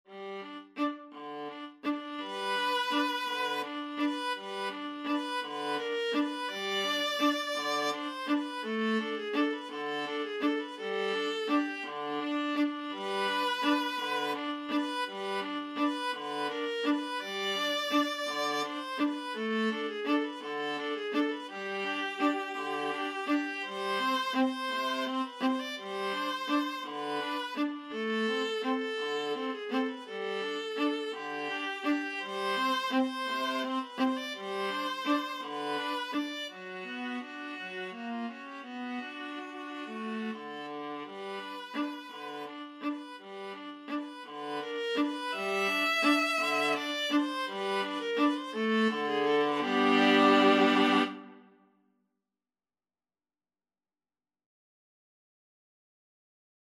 Tempo di valse =168
3/4 (View more 3/4 Music)
Viola Duet  (View more Easy Viola Duet Music)
Classical (View more Classical Viola Duet Music)